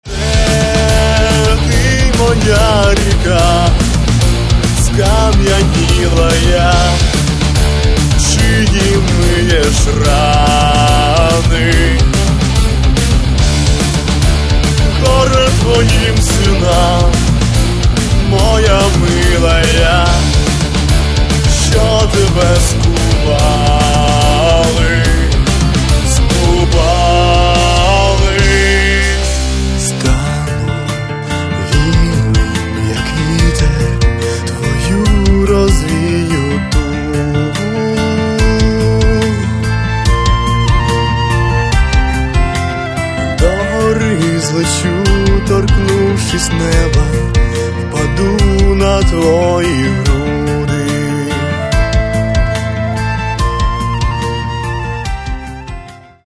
Каталог -> Рок и альтернатива -> Фольк рок